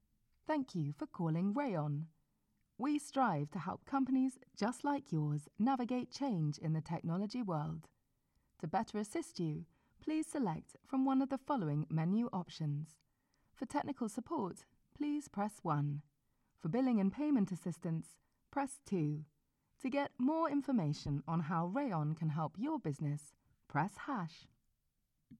Inglés (Británico)
Comercial, Natural, Versátil, Profundo, Cálida
Telefonía